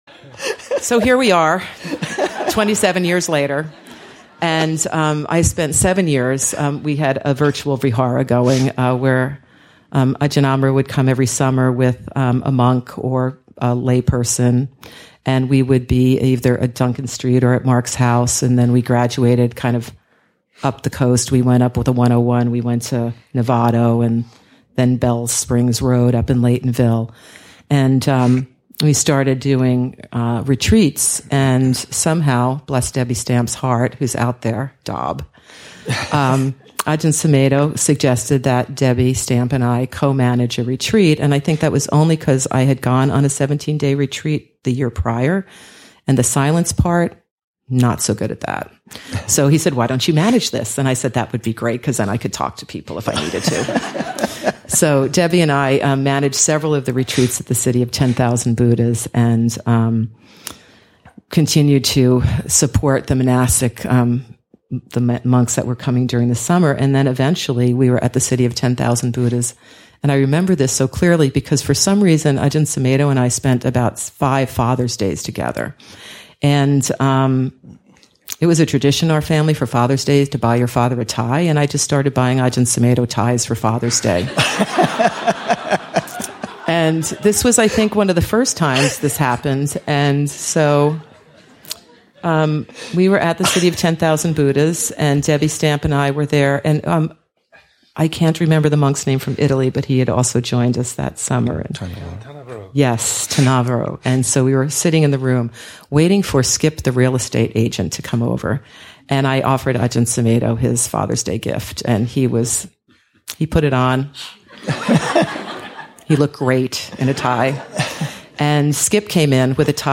2. Story: Offering food to monks and board members; joining the Saṅghapāla Foundation.